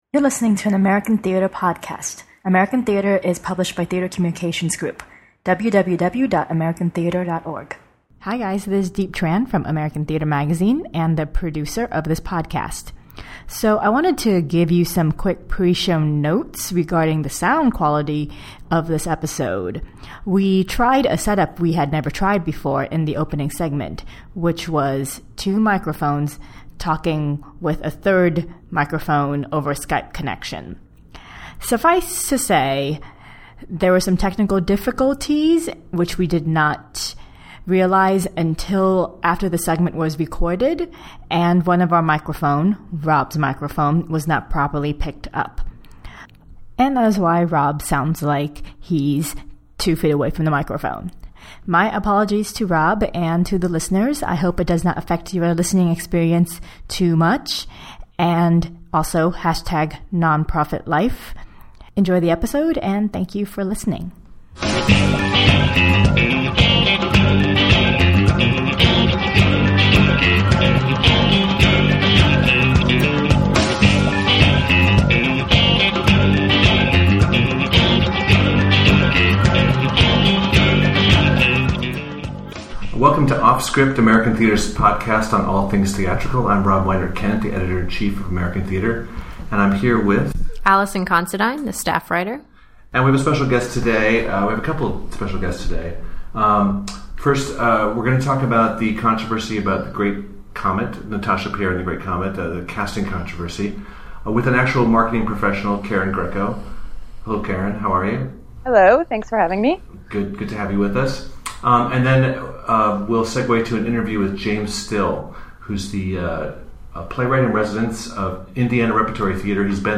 Every other week, the editors of American Theatre curate a free-ranging discussion about the lively arts in our Offscript podcast.